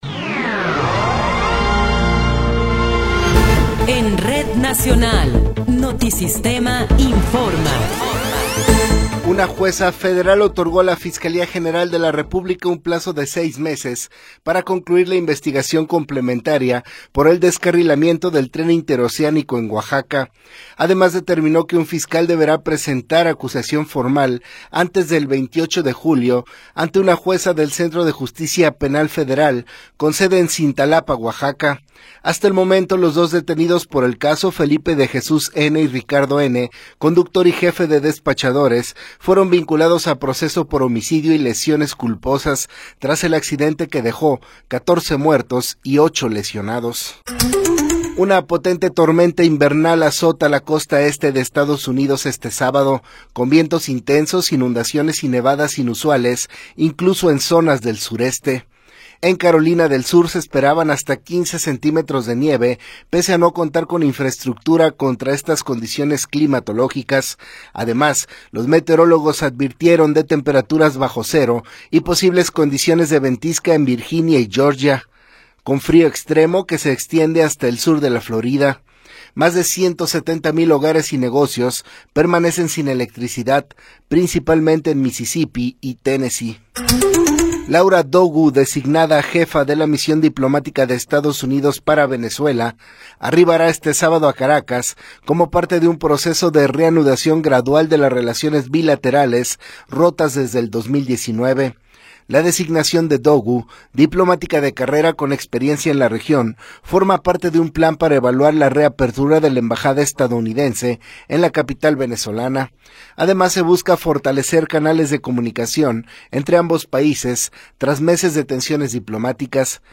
Noticiero 12 hrs. – 31 de Enero de 2026